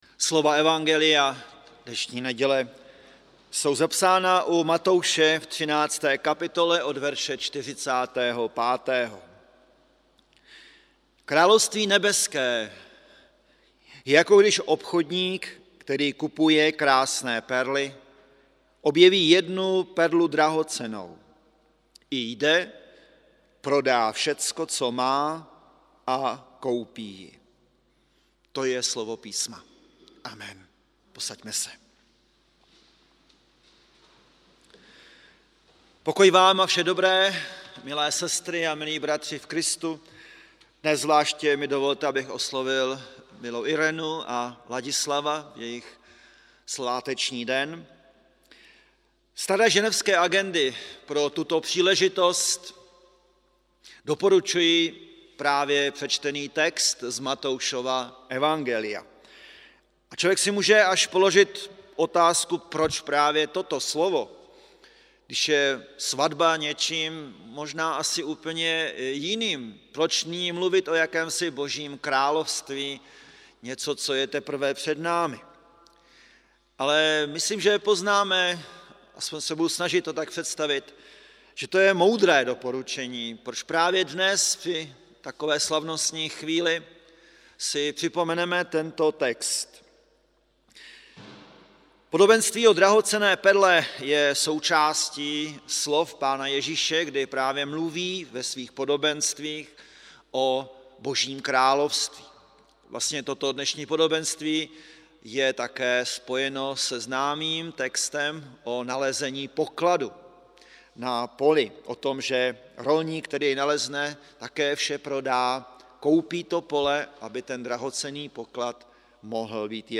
Bohoslužby 6.9.2020 • Farní sbor ČCE Plzeň - západní sbor